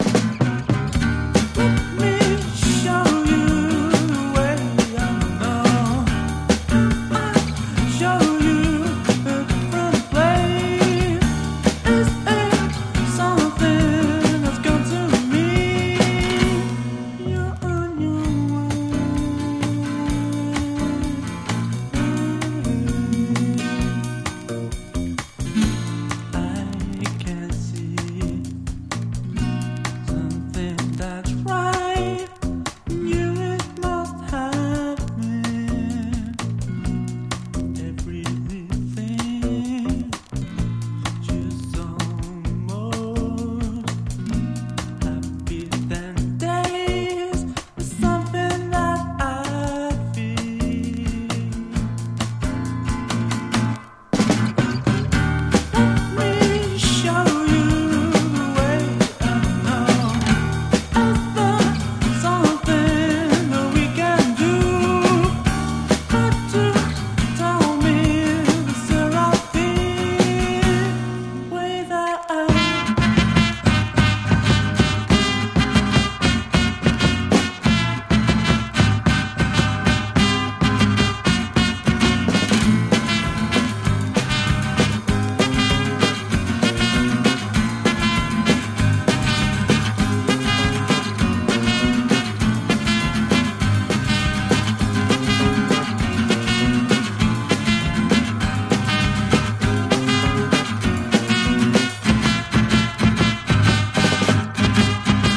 間奏からのめくるめく展開にも心奪われる